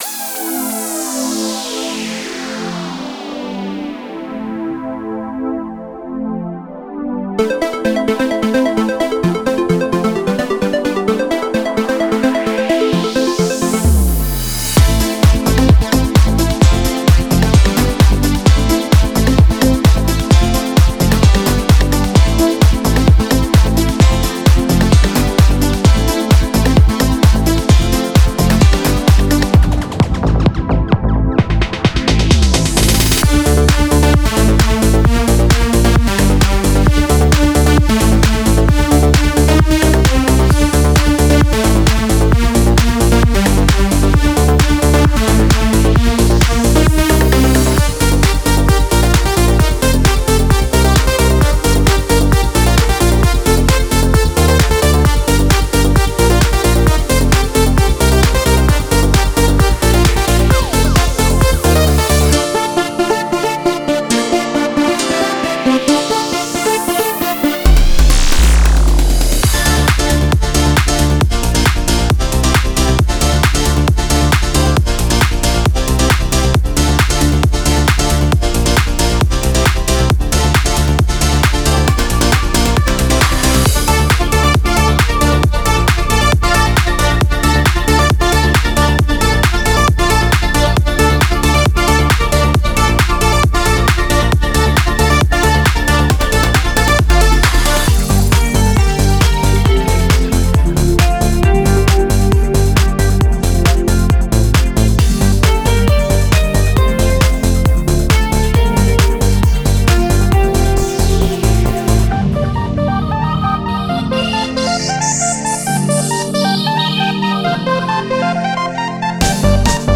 90年代後半から2000年代初頭のクラシックなシンセ、ドラムマシン、FXユニットです。
温かみのあるパンチ、クリスタルのようなリード、粗さのあるベースが、ダンスミュージックの一世代を形作りました。
デモサウンドはコチラ↓
Genre:House
130, 132, 133, 135, 136 BPM
224 Wav Loops (Basses, Synths, Drums, Fx & more)